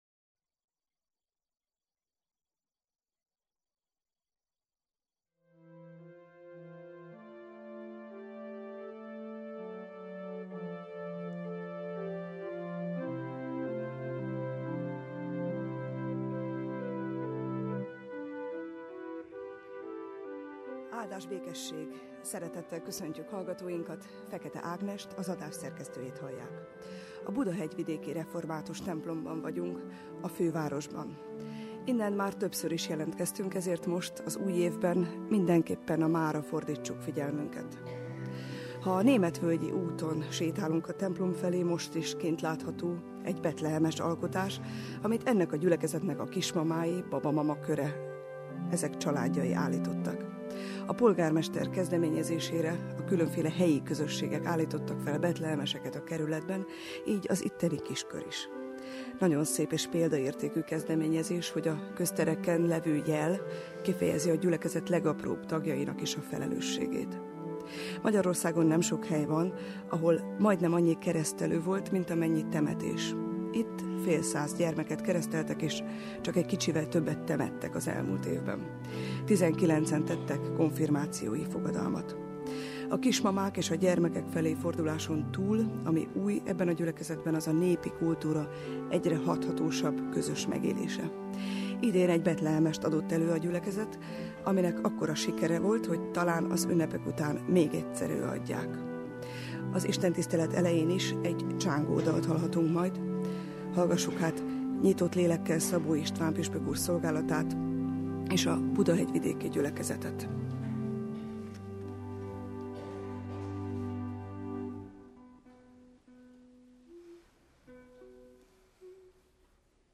Lukács 5:1-11 Újra és újat - hirdette Szabó István püspök a Budahegyvidéki Református Egyházközség templomában. Az újévi igehirdetést a Kossuth Rádió január harmadikán, vasárnap közvetítette.